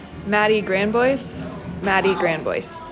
Name Pronunciation: